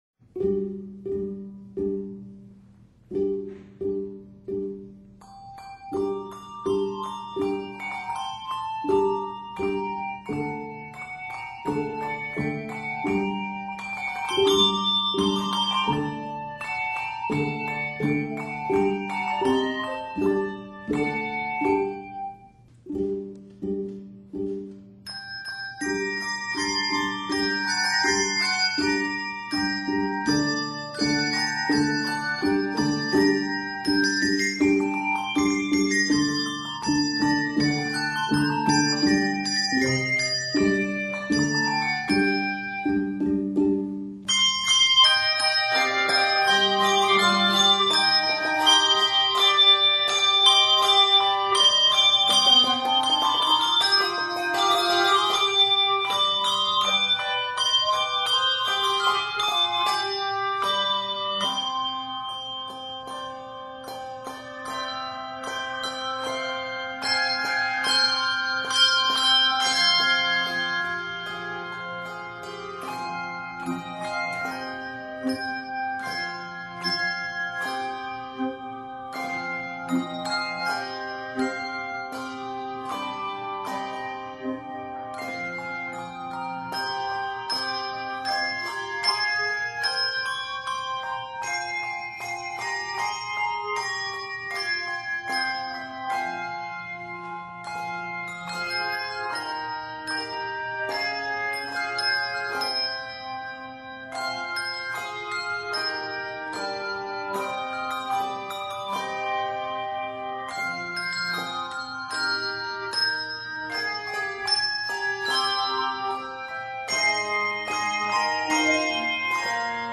Ringers will enjoy the challenge of the running 16th notes.